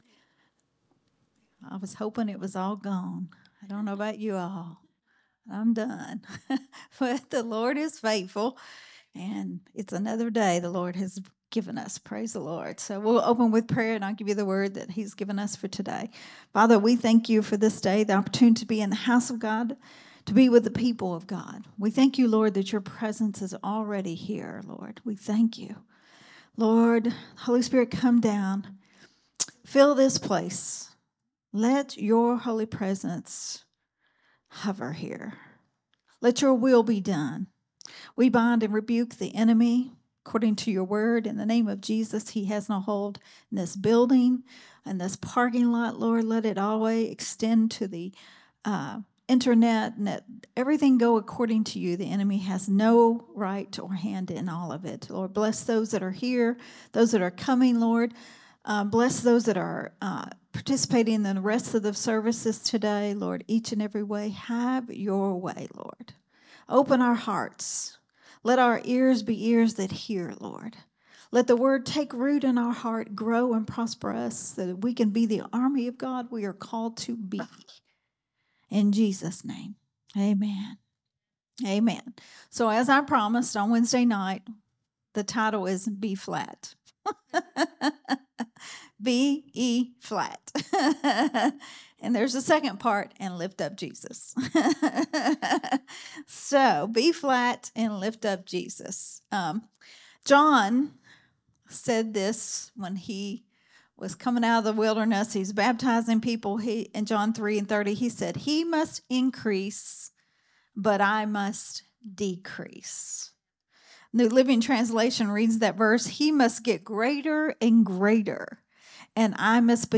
a Sunday Morning Risen Life teaching
recorded at Growth Temple Ministries on Sunday